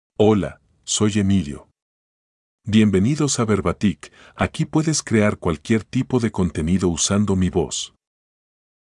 Emilio — Male Spanish AI voice
Emilio is a male AI voice for Spanish (Dominican Republic).
Voice sample
Listen to Emilio's male Spanish voice.
Emilio delivers clear pronunciation with authentic Dominican Republic Spanish intonation, making your content sound professionally produced.